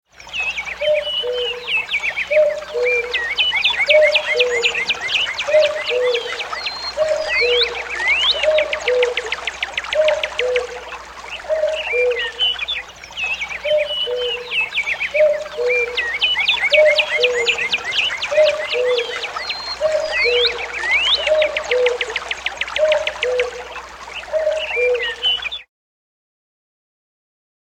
На этой странице собраны натуральные звуки кукушки, которые можно скачать или слушать онлайн.
Звук кукушки в лесной чаще — 2 вариант